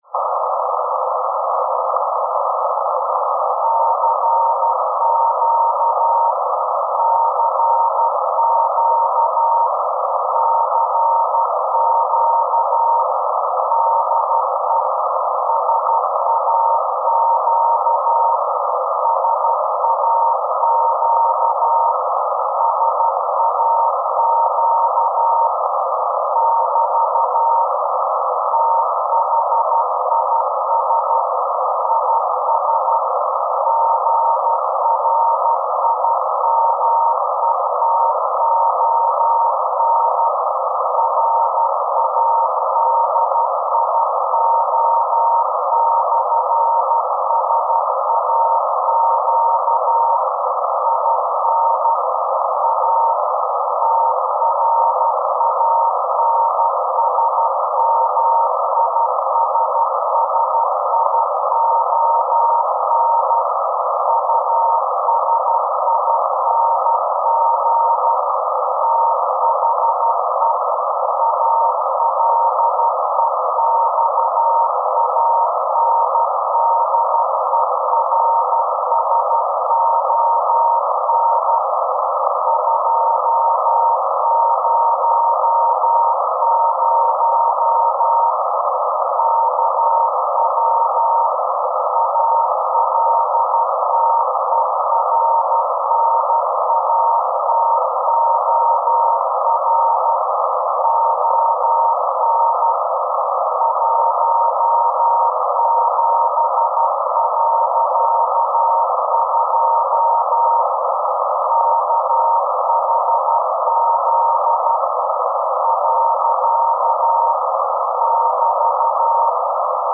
Empfang war recht gut möglich nach Ausblenden eines breitbandigeren Störers in der Umgebung (Trotz Standort im technikfernen Garten) durch Antennenschwenk. Als Empfänger diente ein Grundig Satellit 700 und zum Aufzeichen ein Digitalrecorder LS-10 von Olympus.